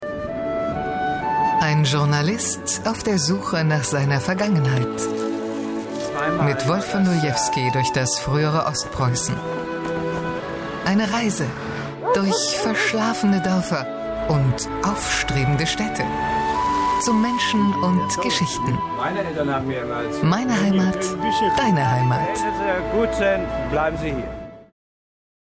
Ein altersloser Alt, von seriös bis sinnlich.
Sprechprobe: Sonstiges (Muttersprache):
A smooth voice with a touch of authority.